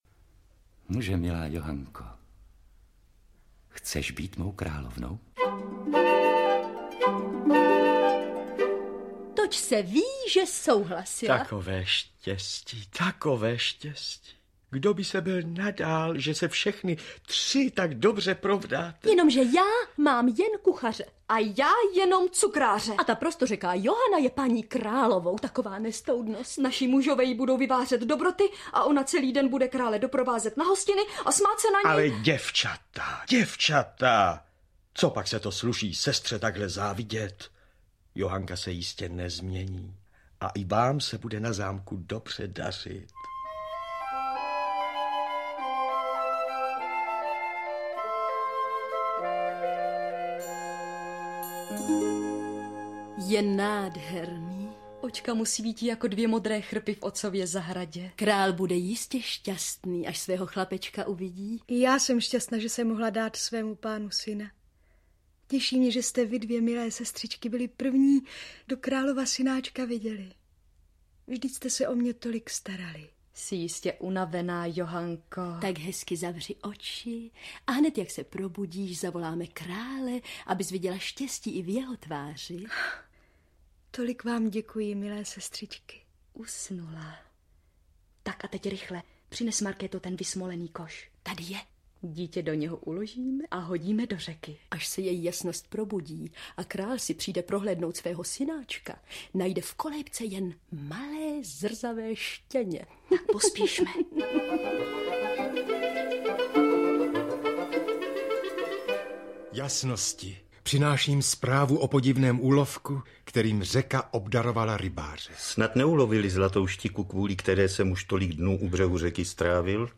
Pohádky Boženy Němcové audiokniha
Ukázka z knihy
Audiokniha Pohádky Boženy Němcové - obsahuje čtyři z nejznámějších pohádek Boženy Němcové, z nichž dvě známe z nestárnoucího filmového zpracování. V dramatizaci se střídají slavní herci odcházející generace, aby nás vtáhli do pohádkových příběhů, které poslouchají děti už desítky let.